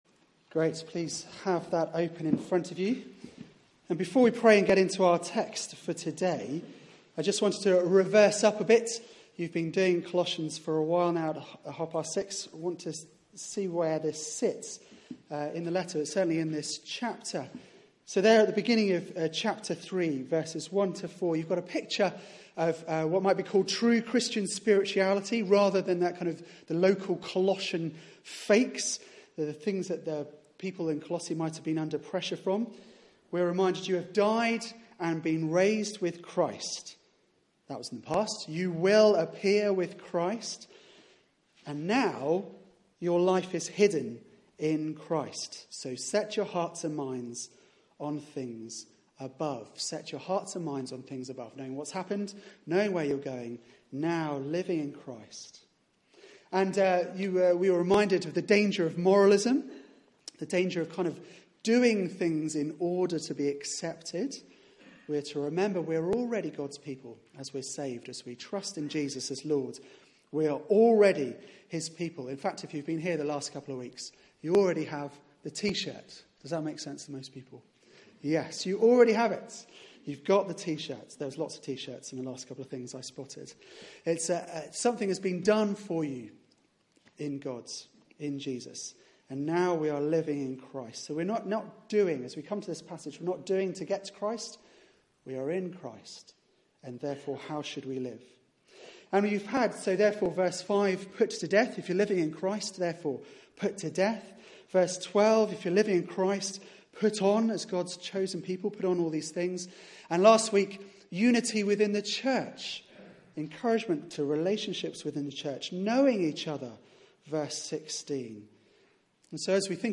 Media for 6:30pm Service on Sun 27th Nov 2016 18:30 Speaker
Series: Rooted in Christ Theme: Christ at home and work Sermon